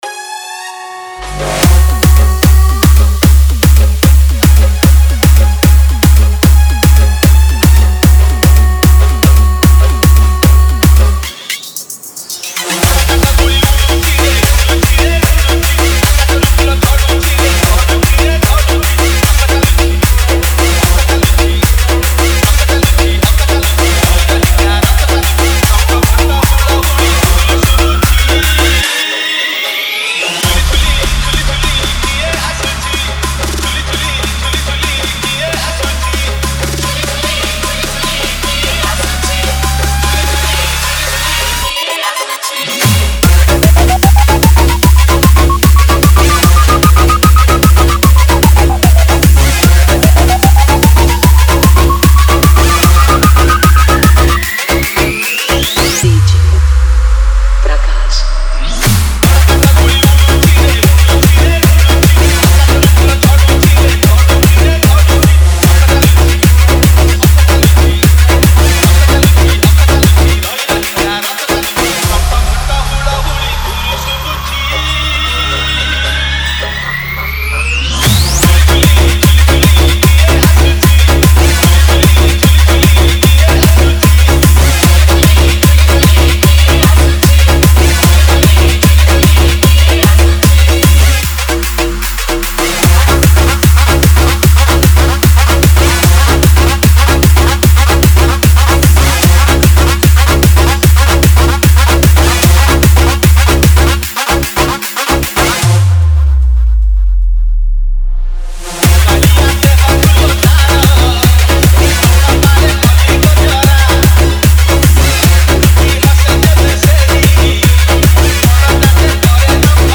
Bhajan Dj Song Collection 2021